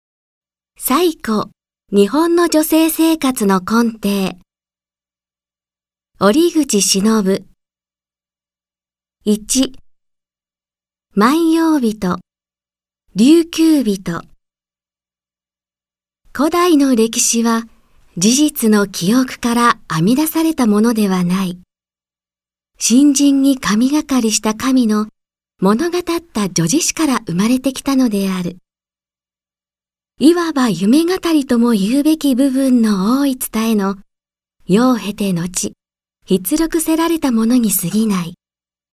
• 弊社録音スタジオ
朗読ＣＤ　朗読街道113
朗読街道は作品の価値を損なうことなくノーカットで朗読しています。